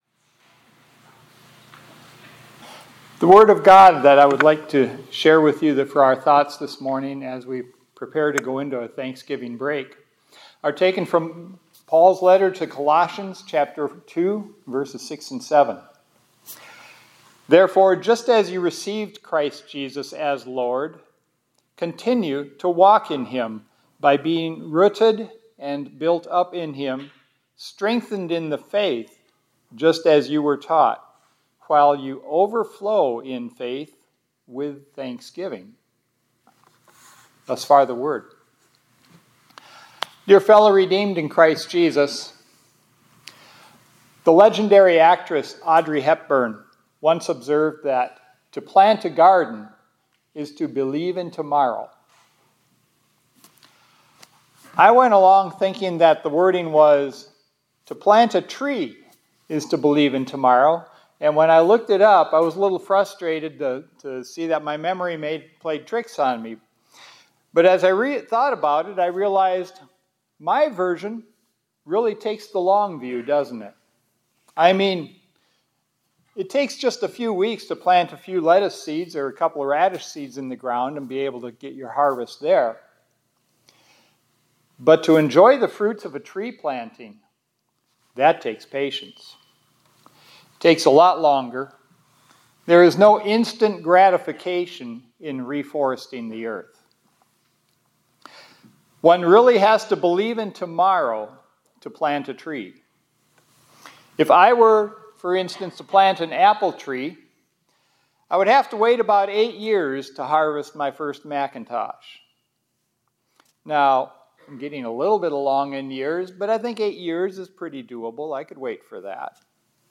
2024-11-26 ILC Chapel — For a Fully Thankful Heart, Look to Your Roots